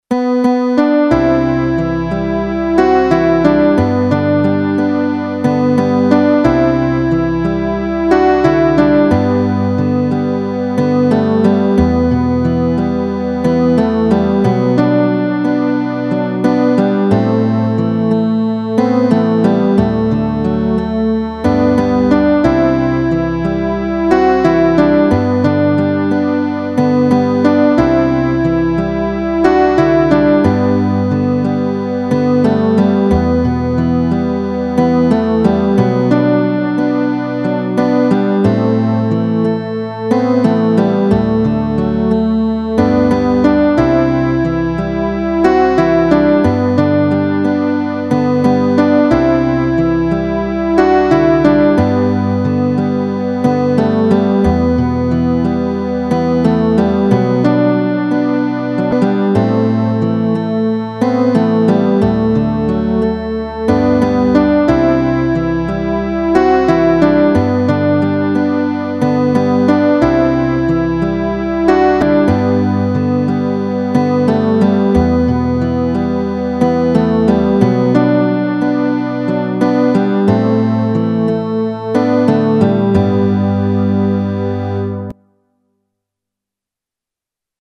A devotional praise and worship to our protector Lord and God.